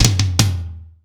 ROOM TOM3C.wav